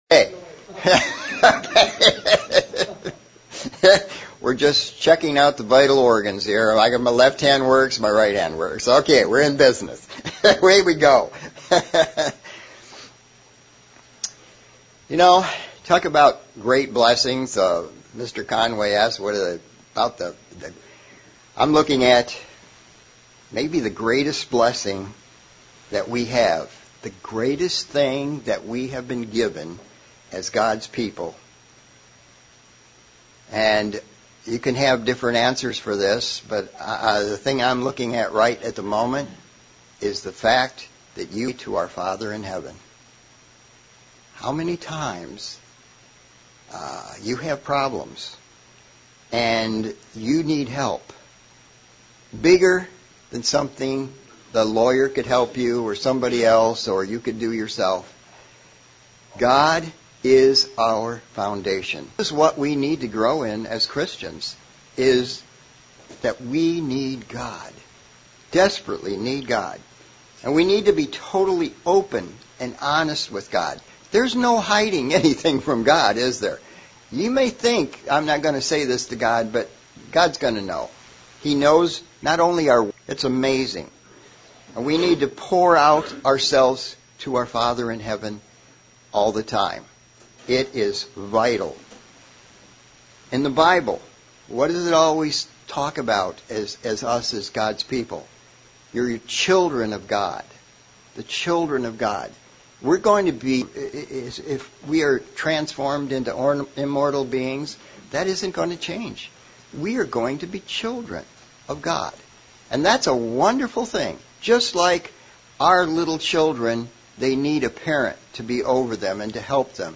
Sermon looking at ways we should be thankful for God's mercy, his loving kindness, and his forgiveness and why we should be thankful for these great blessings that allow us to be part of his kingdom and his plan.